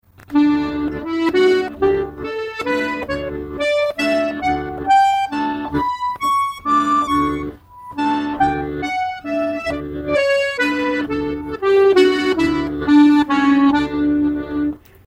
Accordeon diatonique et Musiques Traditionnelles
jouer la gamme avec B/a RE tout le long à la main gauche en rythme de valse et términer par ré do ré.
Gamme de RE tiré sur 2 octaves , droite gauche.